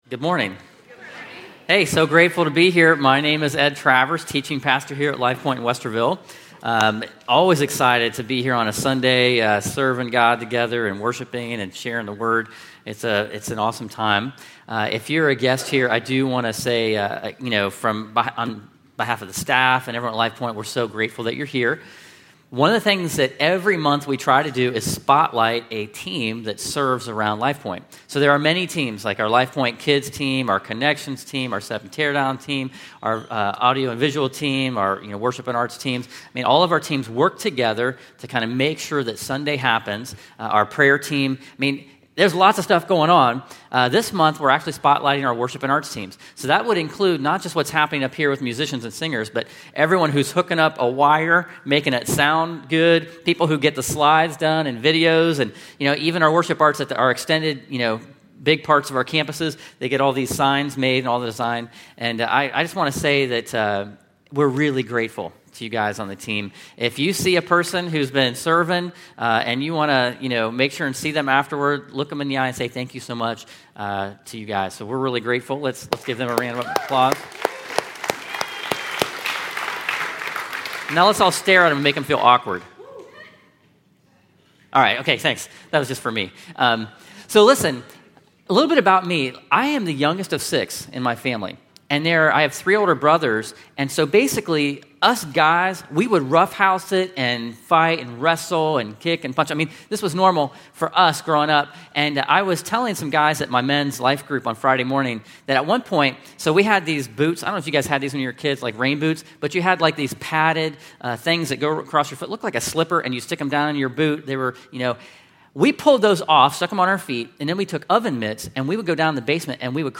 “The Battle for Freedom” where we learn that God provides freedom for His people. This sermon is part of the series “Into the Wild.” The sermon was given at Lifepoint Westerville.